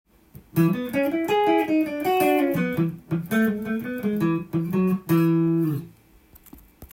２：３９～から明るい雰囲気のメカニカルな音階になっています。
スケールはEミクソリディアンスケールを使用していますが
T-SQUAREらしいフュージョンチックな雰囲気になっています。
１６分音符が多いので右手のピッキングはオルタネイトで正確に